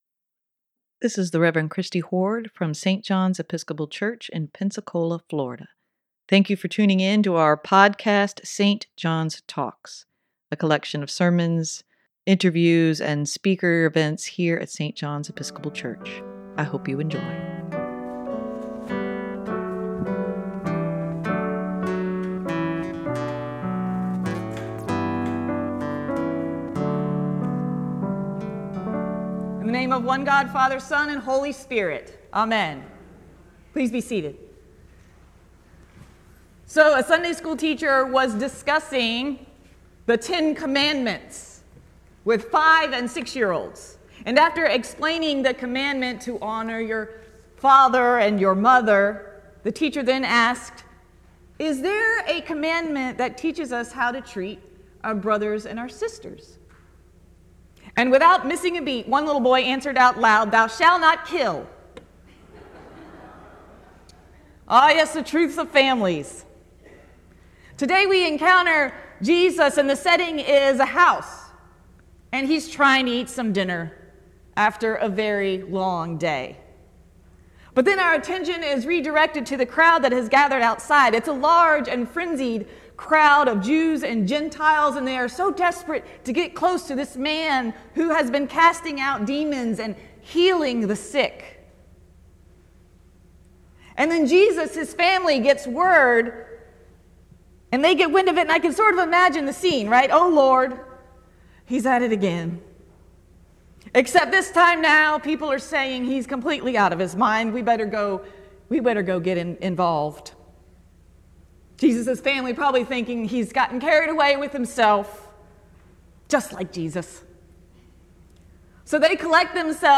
A sermon for Sunday, June 6, 2021.